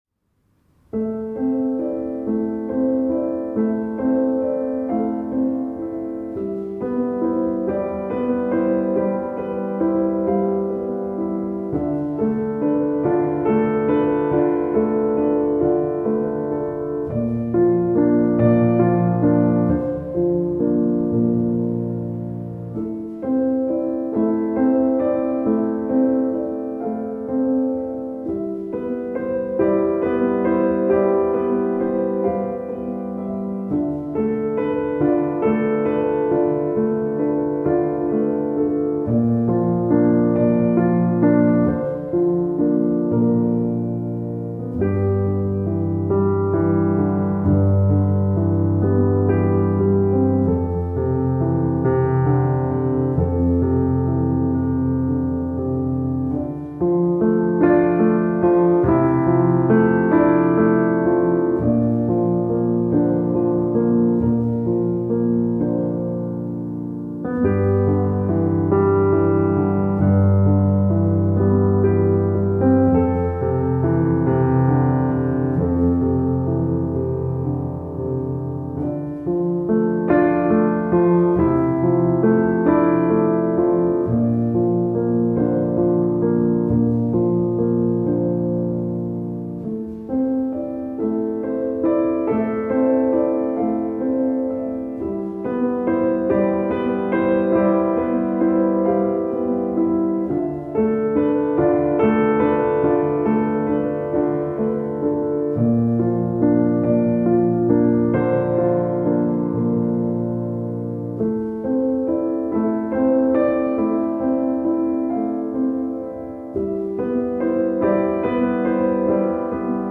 سبک آرامش بخش , پیانو , کلاسیک , موسیقی بی کلام